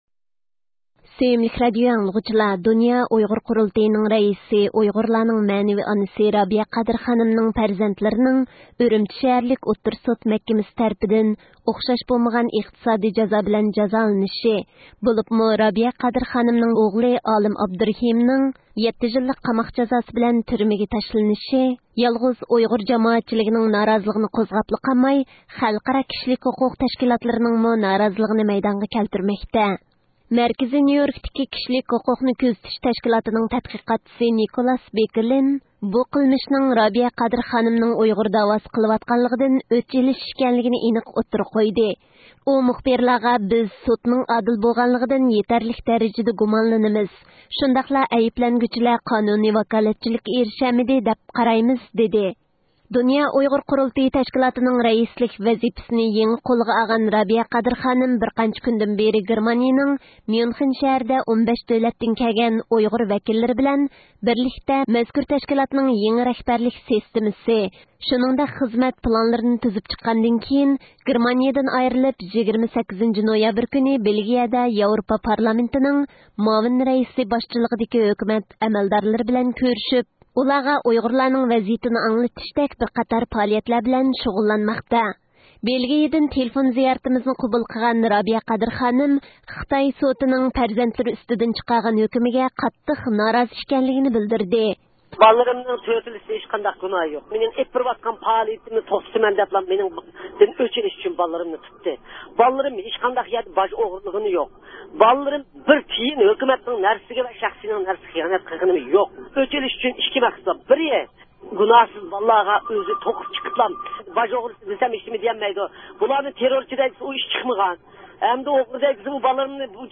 بېلگىيىدىن تېلېفۇن زىيارىتىمىزنى قوبۇل قىلغان رابىيە قادىر خانىم، خىتاي سوتىنىڭ پەرزەنتلىرى ئۈستىدىن چىقارغان ھۆكۈمىگە قاتتىق نارازىلىق بىلدۈردى.